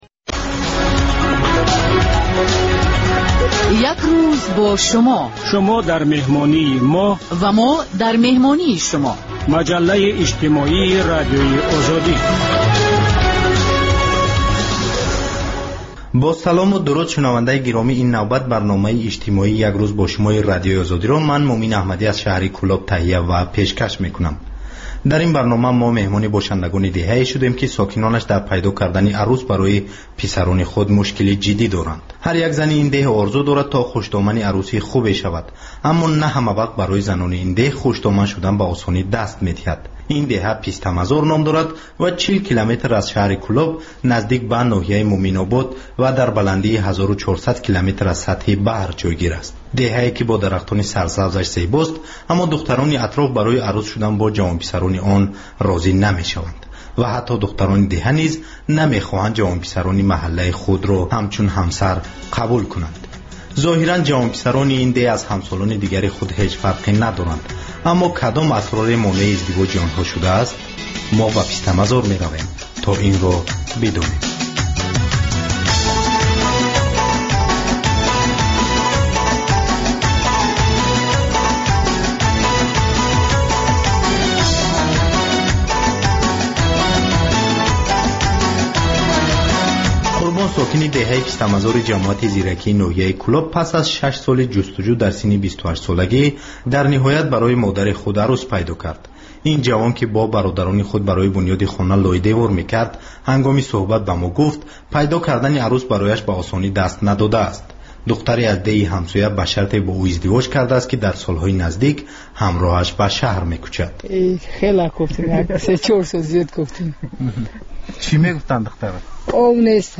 Новости стран Центральной Азии. Специальная программа на русском языке.